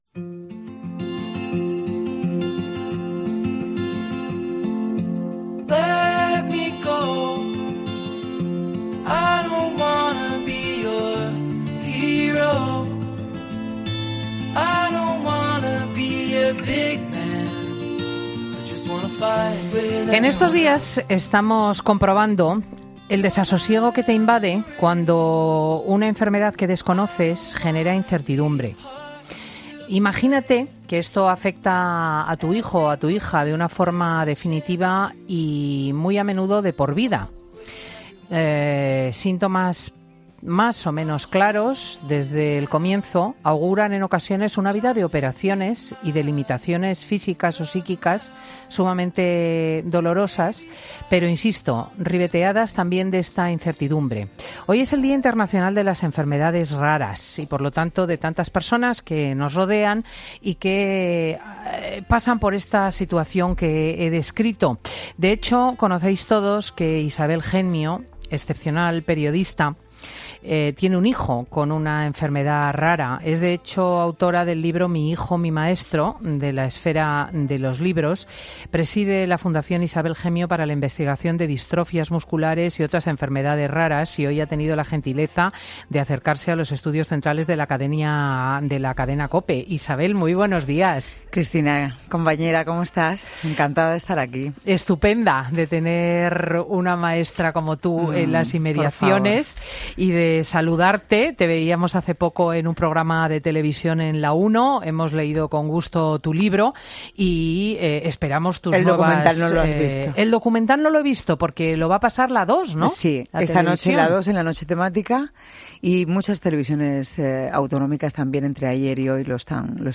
La famosa periodista y presentadora de televisión ha pasado por los micrófonos de Fin de Semana con motivo del Día Mundial de las Enfermedades Raras